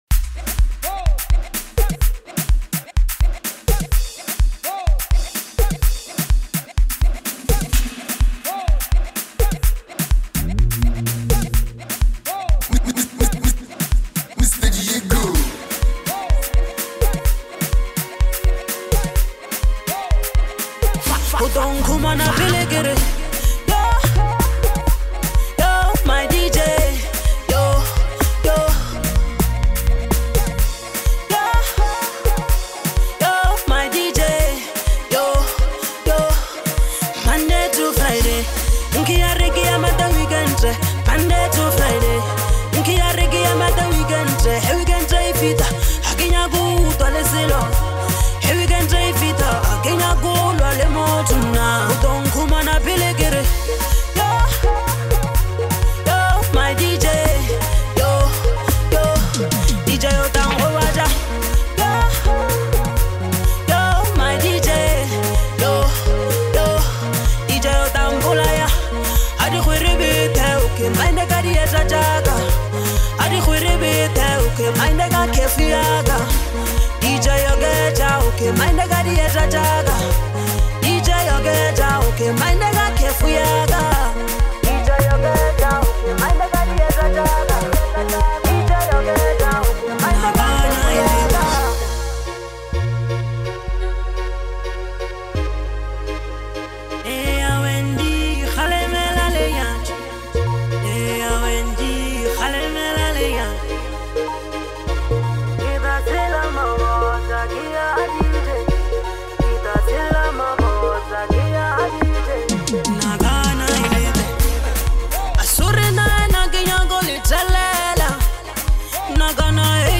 an electrifying anthem
Don’t miss out on this ultimate party banger!